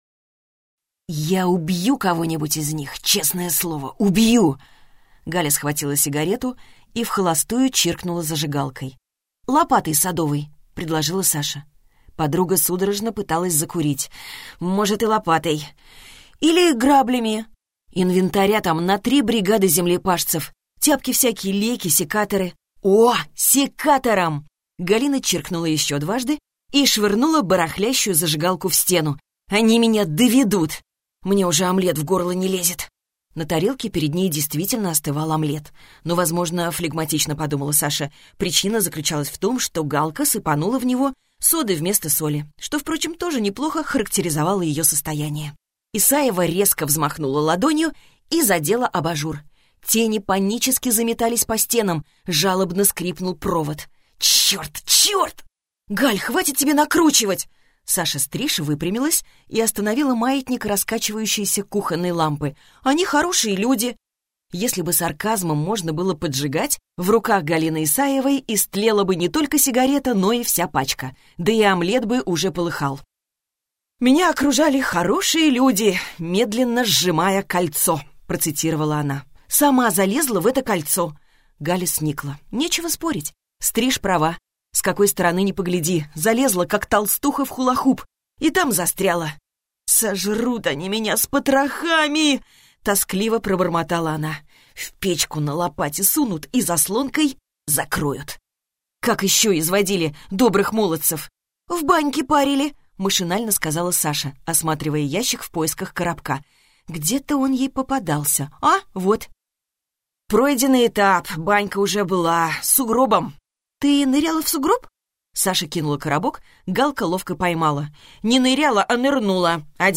Аудиокнига Черный пудель, рыжий кот, или Свадьба с препятствиями - купить, скачать и слушать онлайн | КнигоПоиск